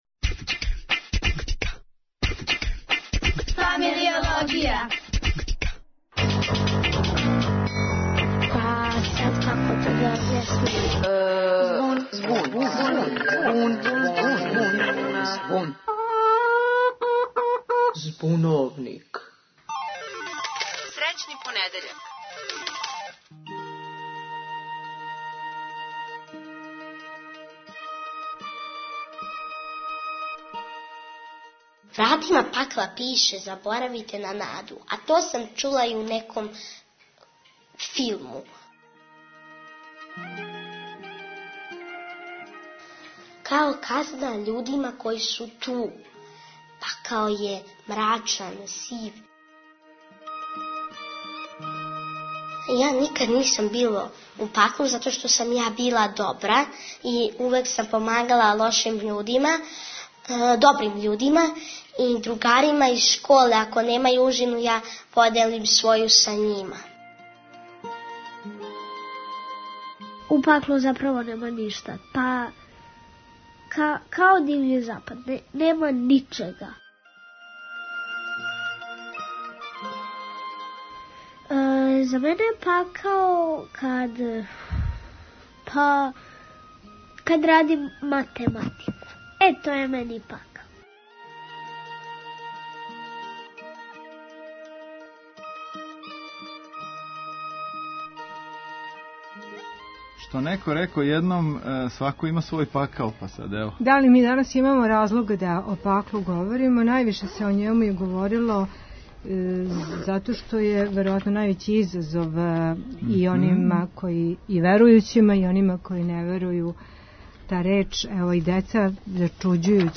Дантеа у Збуновнику глуми Никола Ракочевић. Деца и млади казују о Паклу.